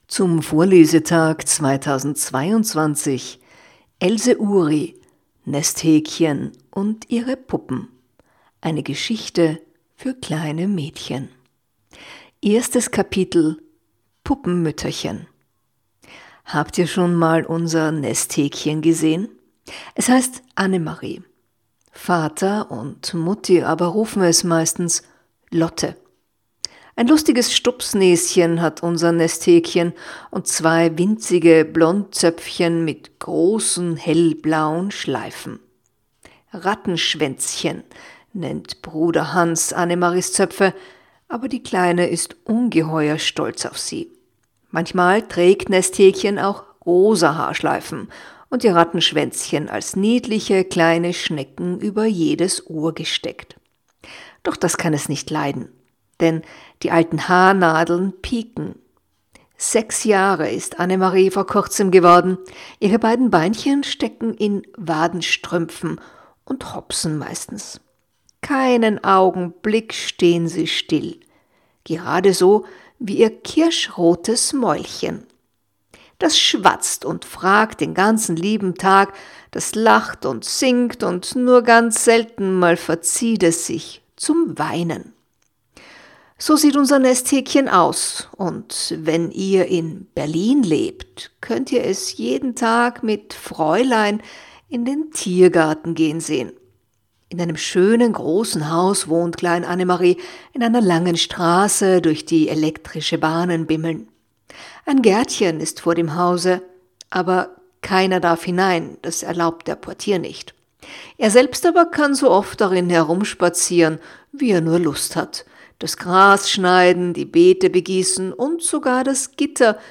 Der von mir heuer am Vorlesetag gelesene Text stammt aus dem bekanntesten Werk, der in Auschwitz ermordeten Else Ury: Nesthäkchen
Lass mich dir vorlesen!